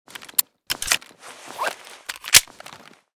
glock_reload.ogg